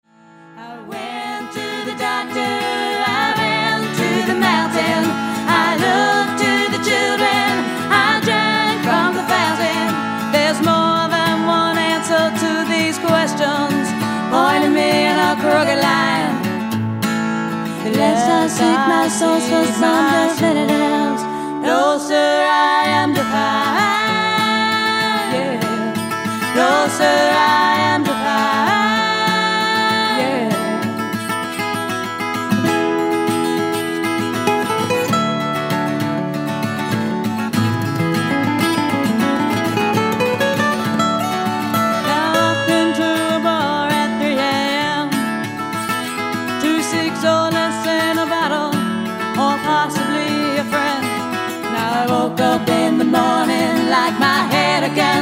cover demo